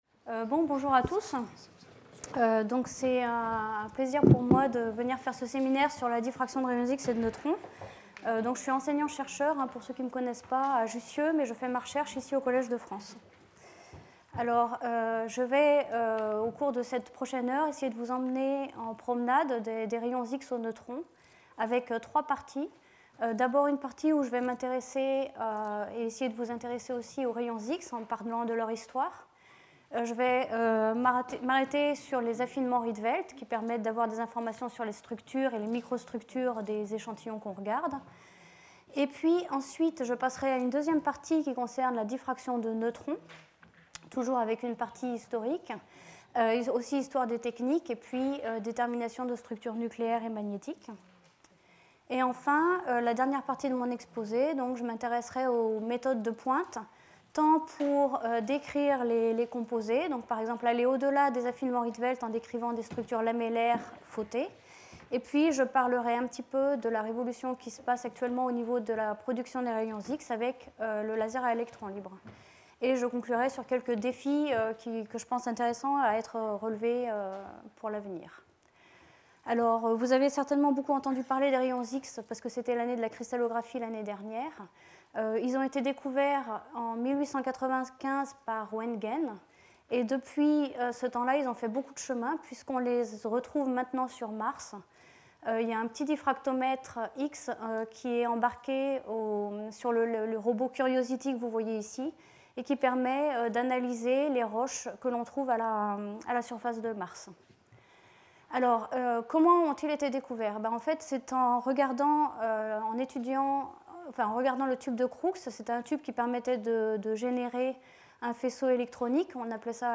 The seminar, illustrated by examples from battery materials research, concludes with a presentation on current and future cutting-edge techniques.